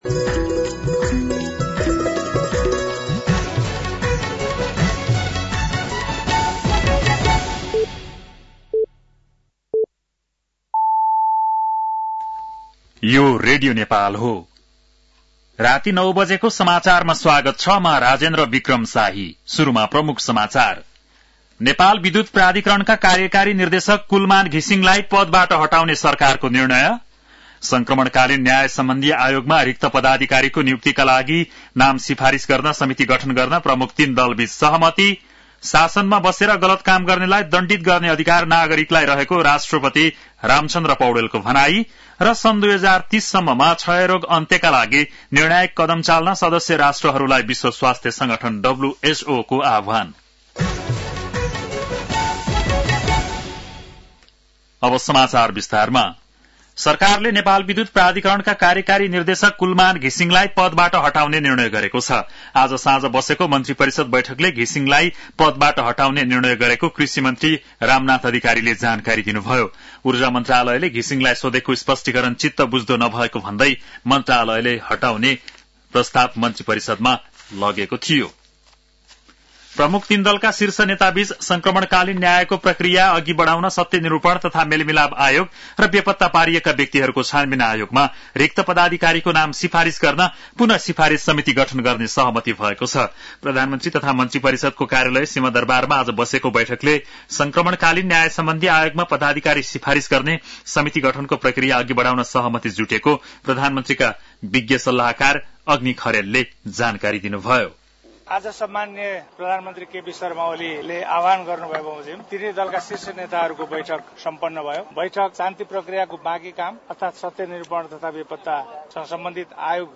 बेलुकी ९ बजेको नेपाली समाचार : ११ चैत , २०८१
9-PM-Nepali-NEWS-12-11.mp3